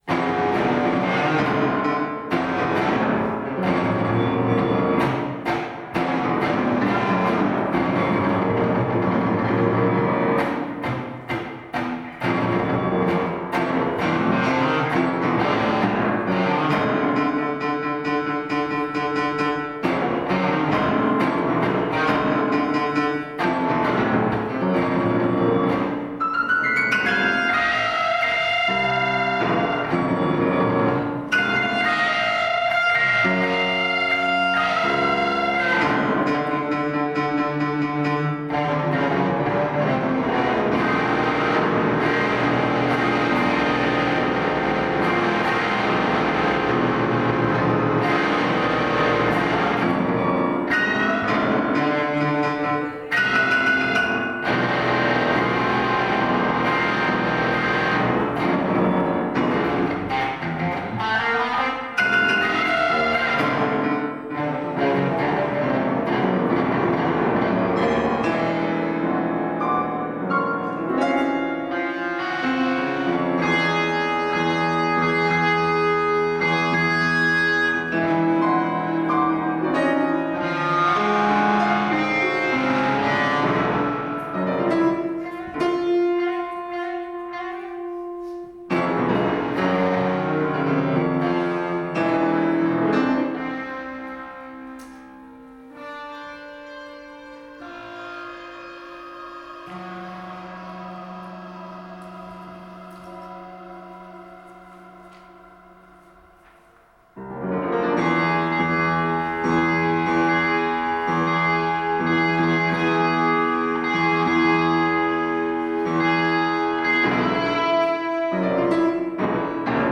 electric guitar
piano